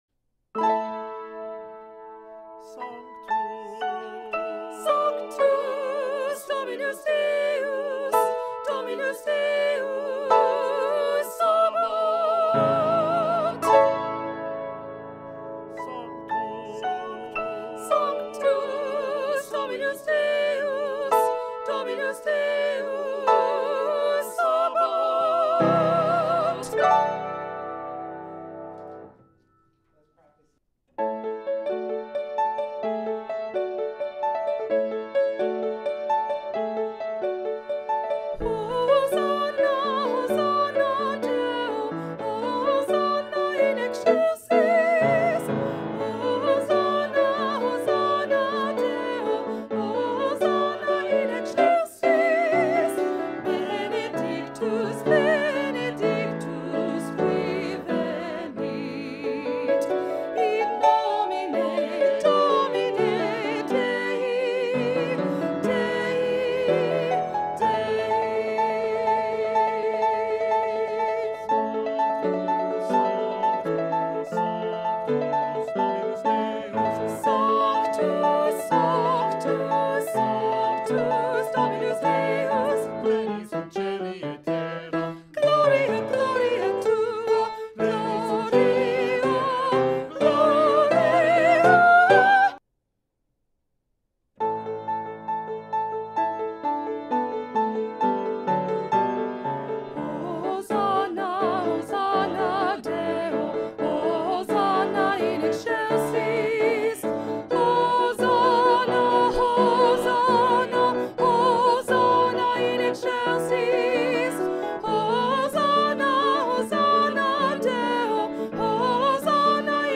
Lo ULTIMO sopranos
Sanctus-festiva-sopranos.mp3